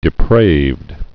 (dĭ-prāvd)